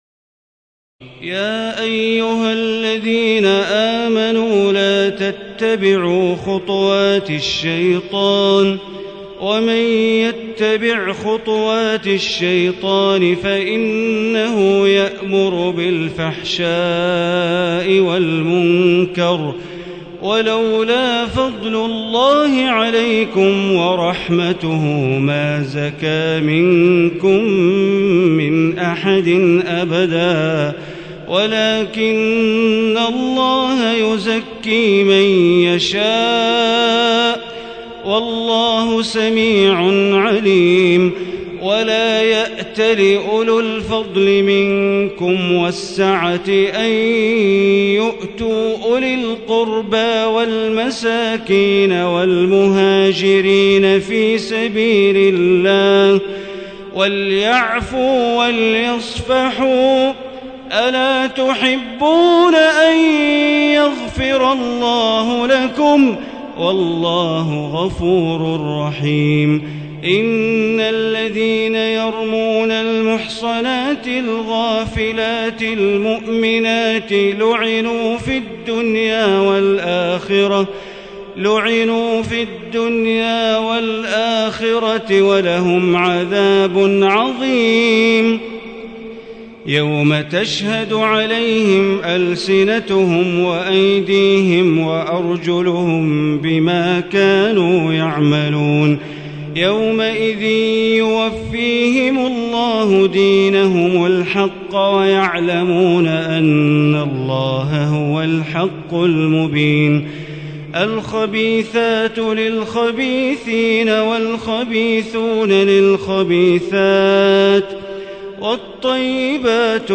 تراويح الليلة السابعة عشر رمضان 1437هـ من سورتي النور (21-64) و الفرقان (1-20) Taraweeh 17 st night Ramadan 1437H from Surah An-Noor and Al-Furqaan > تراويح الحرم المكي عام 1437 🕋 > التراويح - تلاوات الحرمين